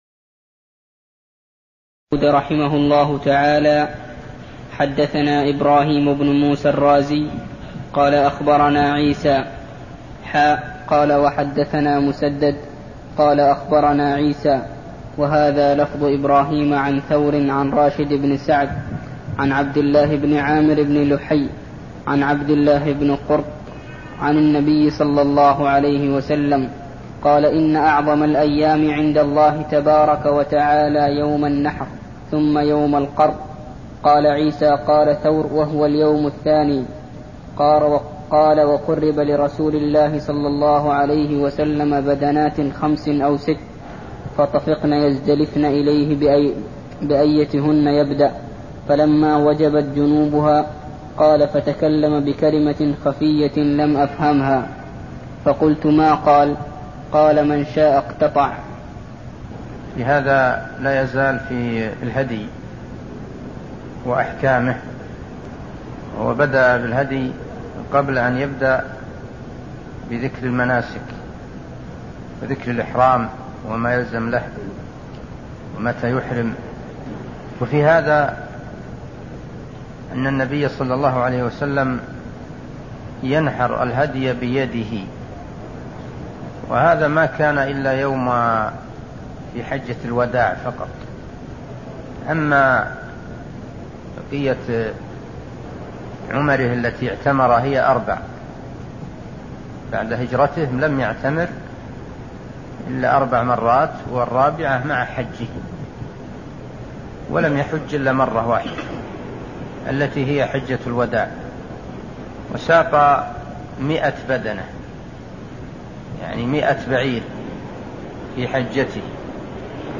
المكان: المسجد النبوي الشيخ: عبدالله الغنيمان عبدالله الغنيمان باب في الهدي اذا اعطب قبل ان يبلغ (14)